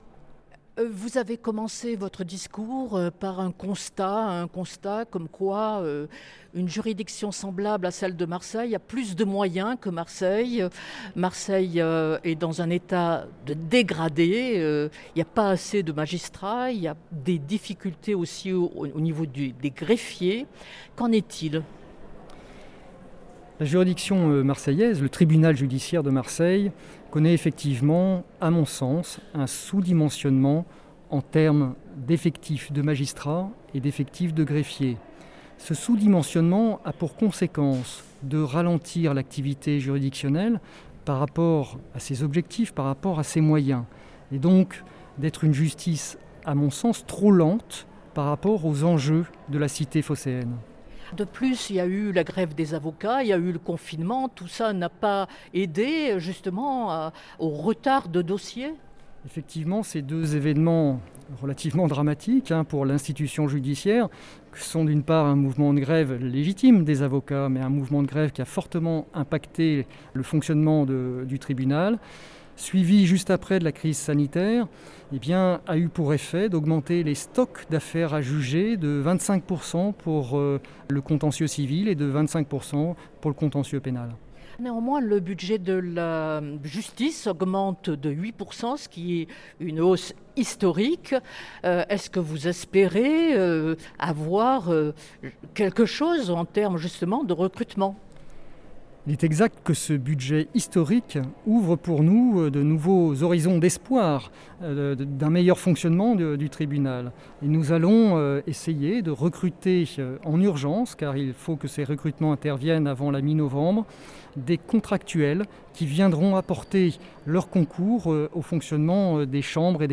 Le président du tribunal judiciaire de Marseille revient enfin sur la lutte contre la délinquance financière. Entretien.
olivier_leurent_02_10_20_president_tribunal_de_mrs.mp3